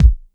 • Classic Rap Kick Drum Sample B Key 402.wav
Royality free kick drum single shot tuned to the B note. Loudest frequency: 119Hz
classic-rap-kick-drum-sample-b-key-402-o6w.wav